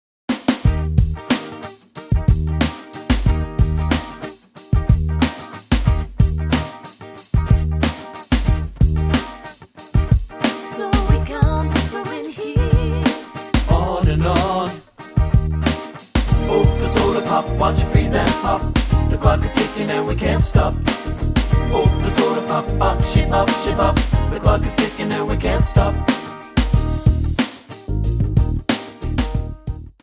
Backing track files: 1990s (2737)
Buy With Backing Vocals.